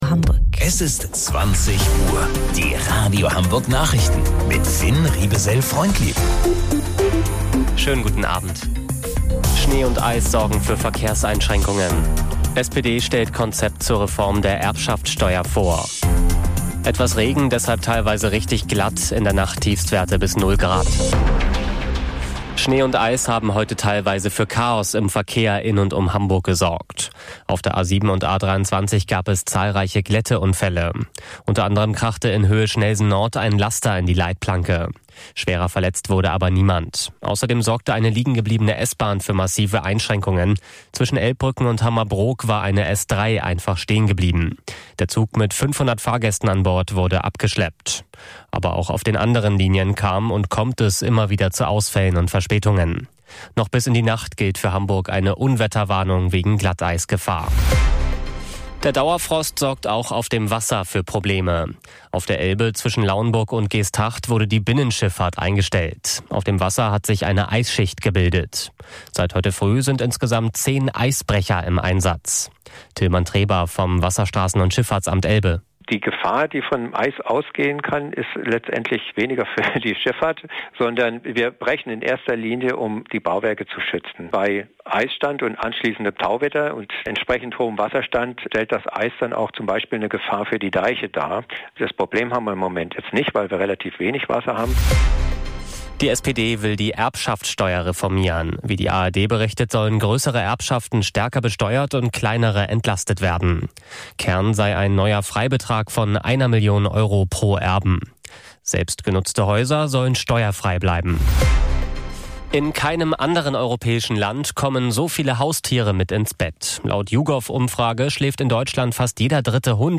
Radio Hamburg Nachrichten vom 12.01.2026 um 20 Uhr